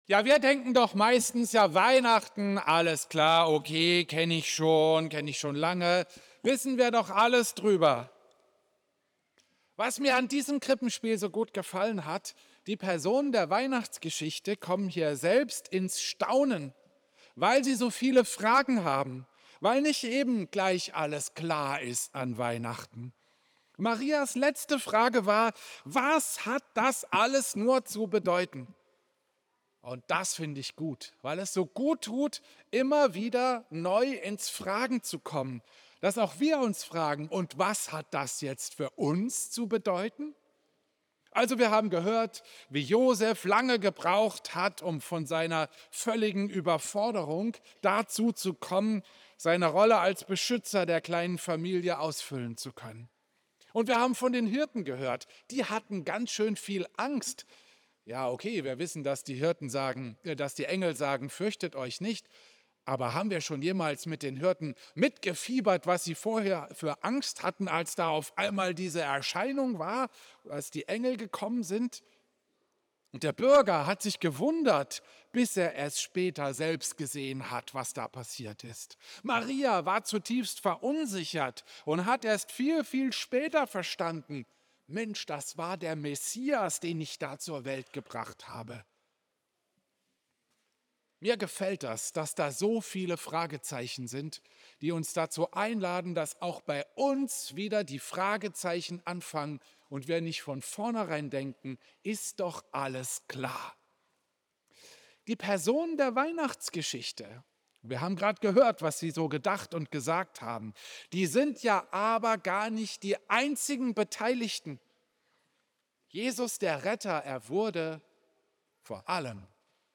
Kurzpredigt
Klosterkirche Volkenroda, 24.